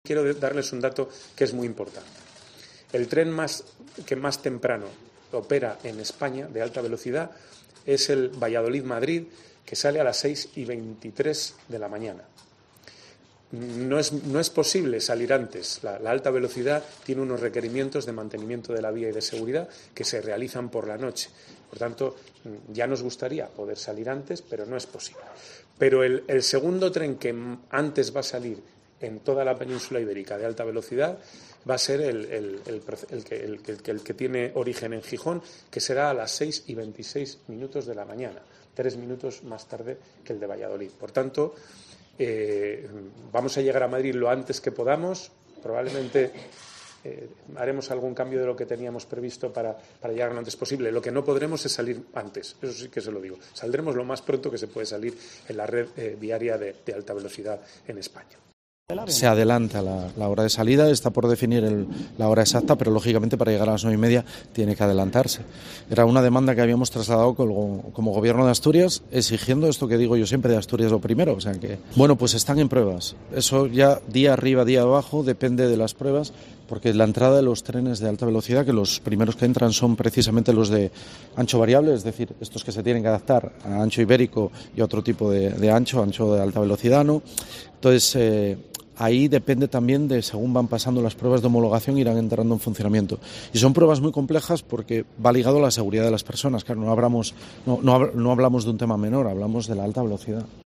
Oscar Puente y Adrián Barbón sobre el horario del primer tren entre Asturias y Madrid